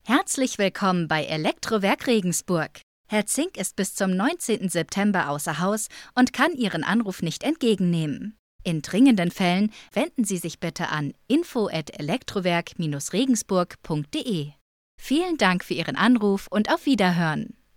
Female
WERBUNG_SEMRUSH.mp3
Microphone: Neumann TLM103, Rode NT1 A